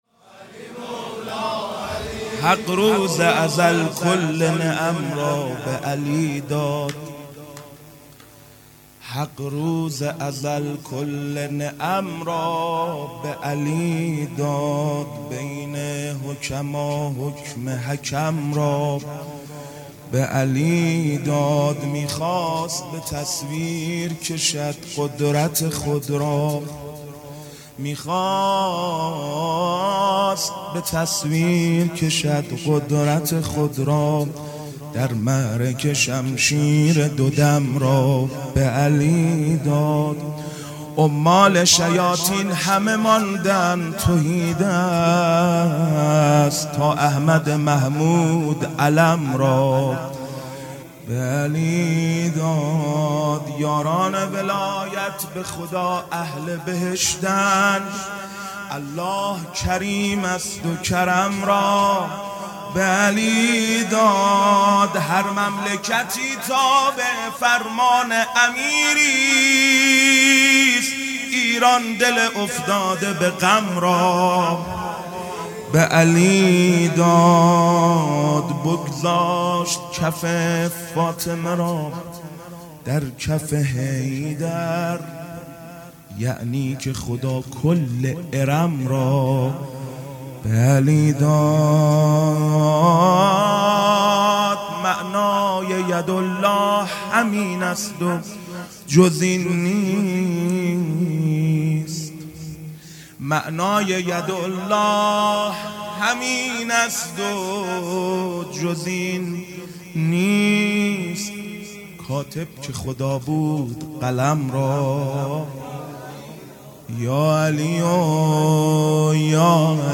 هیئت دانشجویی فاطمیون دانشگاه یزد
مدح
ولادت سرداران کربلا | ۲۰ فروردین ۹۸